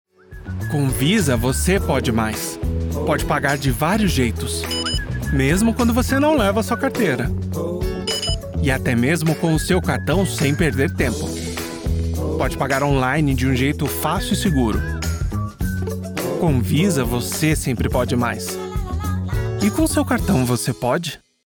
Meine hochmoderne Studioeinrichtung gewährleistet eine außergewöhnliche Klangqualität für jedes Projekt.
Perfekte Akustikkabine
Mikrofone sE Eletronics T2